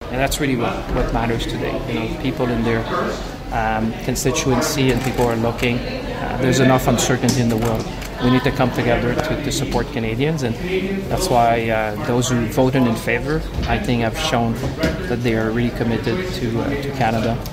Speaking with media after the vote, Finance Minister François-Philippe Champagne stated that what matters today is that parliamentarians who voted in favour put Canadians first.